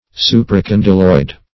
Search Result for " supracondyloid" : The Collaborative International Dictionary of English v.0.48: Supracondylar \Su`pra*con"dy*lar\, Supracondyloid \Su`pra*con"dy*loid\, a. (Anat.)